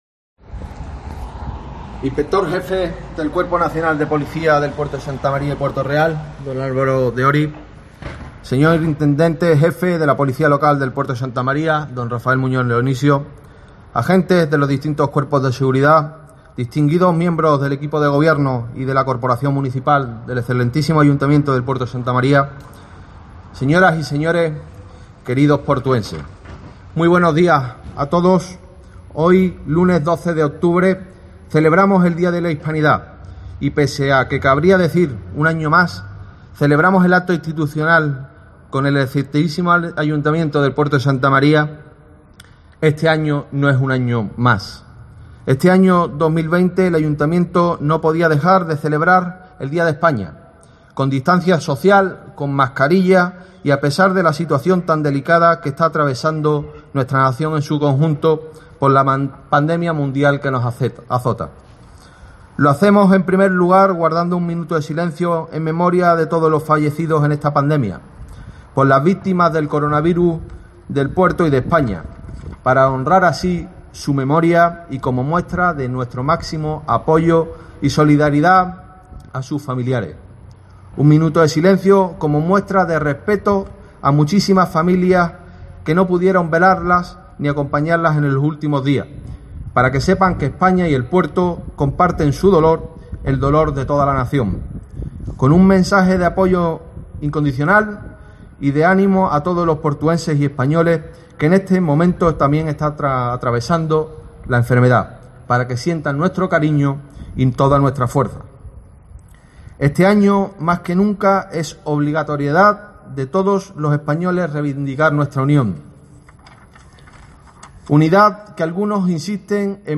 Un acto institucional con izado de bandera en plena plaza Isaac Peral e interpretación del Himno Nacional ha permitido escuchar un mensaje del alcalde, el popular Germán Beardo, cargado de referencias a la unidad de España, a la defensa de la Monarquía y los esfuerzos necesarios para salir de las situaciones sanitarias y económicas a las que somete la pandemia de Covid-19.